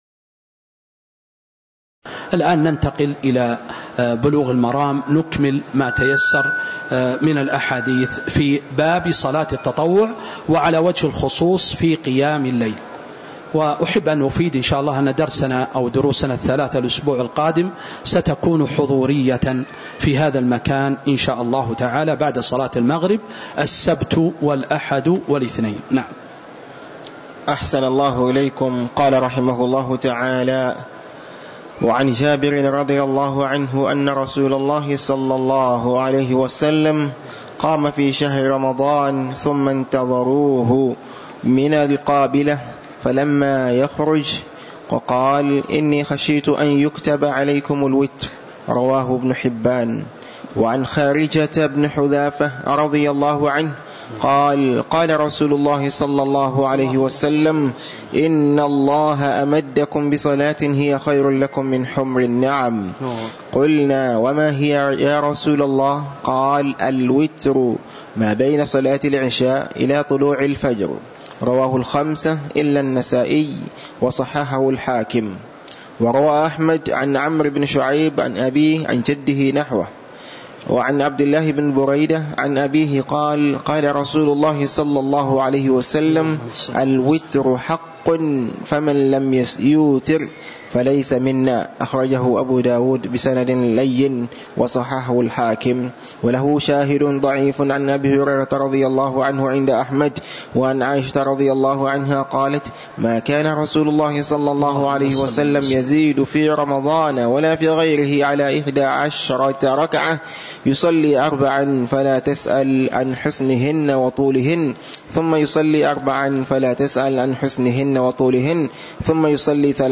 تاريخ النشر ٢٠ جمادى الأولى ١٤٤٥ هـ المكان: المسجد النبوي الشيخ